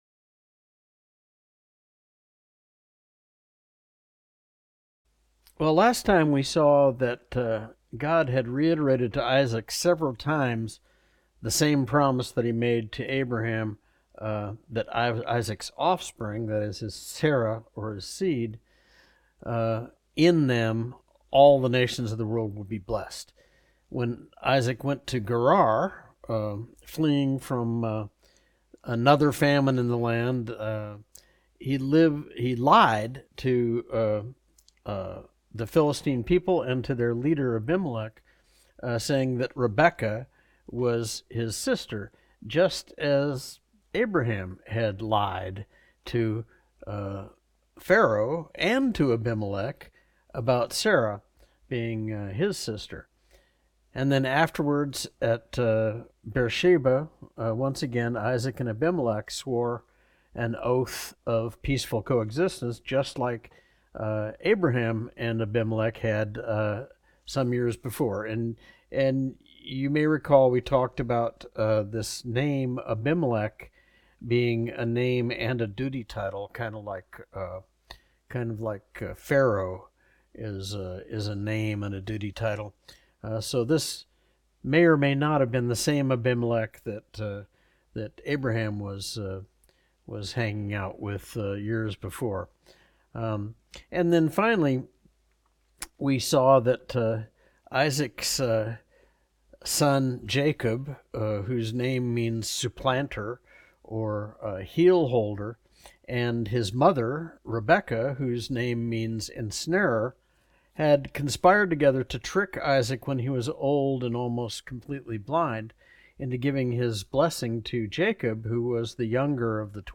Study Type - Adult Lesson